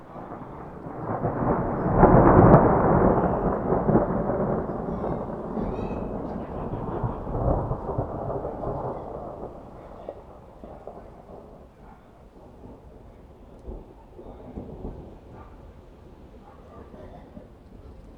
• field thunder spring 6.wav
Beautiful rainy spring night, enchanting us by some great thunders and rumbles. Recorded with a TASCAM DR 40.
field_thunder_spring_6_ugb.wav